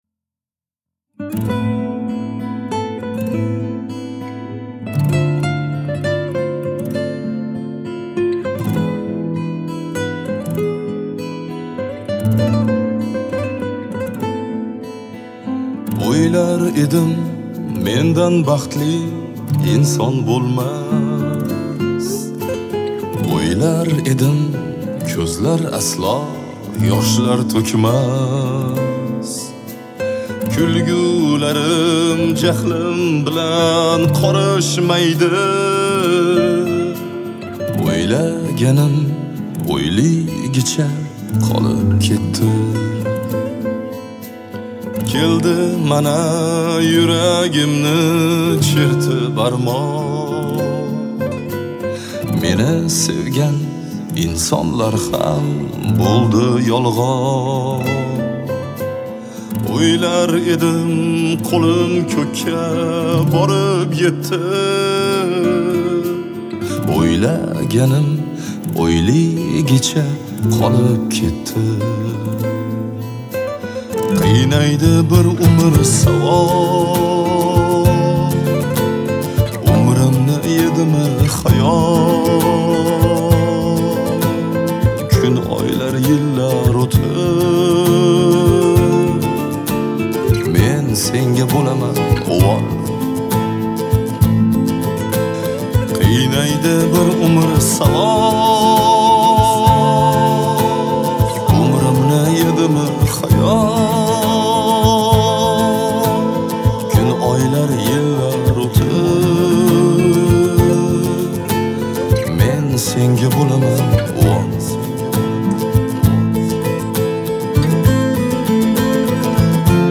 Трек размещён в разделе Узбекская музыка / Поп.